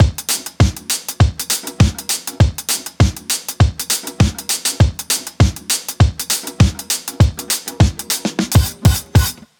Unison Funk - 4 - 100bpm.wav